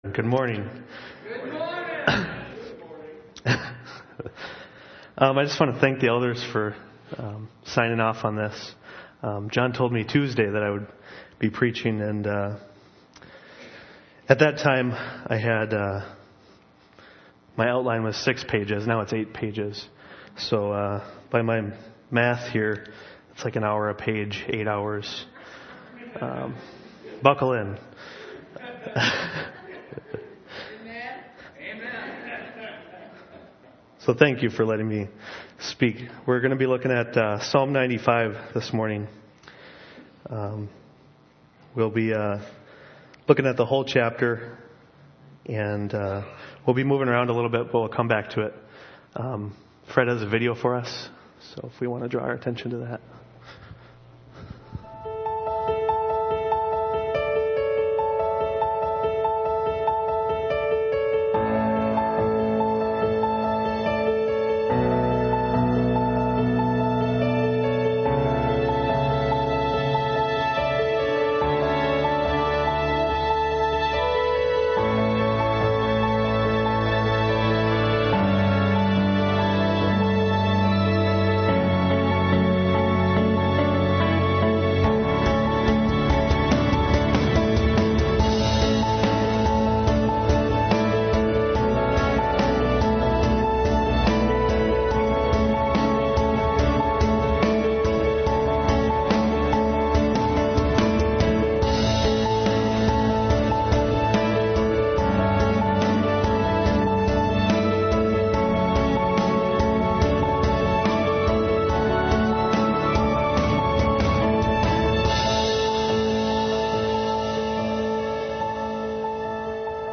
The key text for this sermon is Psalm 95:1-11.